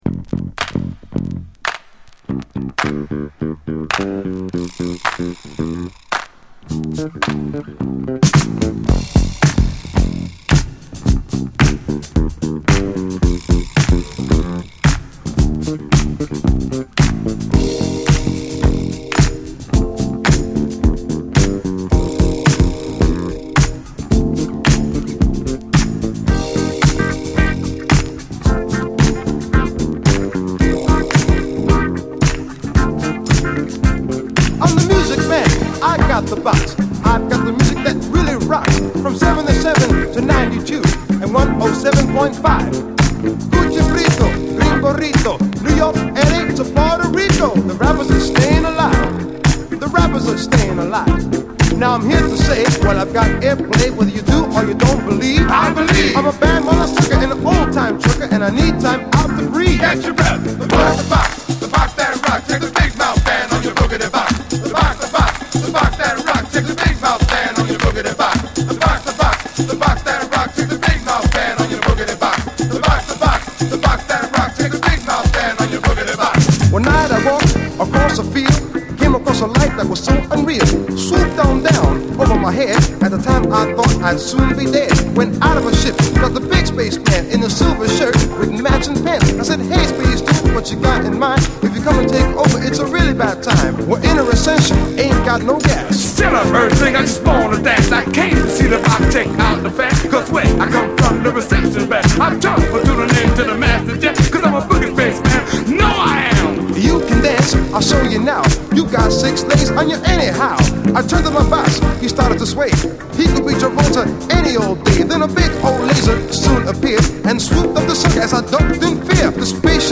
様々なディスコ・ヒットの弾き直しトラックによるB級カヴァーやディスコ・ラップ群が嬉しい！
どちらも後半にラップ入り
オールドスクール・ディスコ・ラップの持つB級感を愛するアナタに！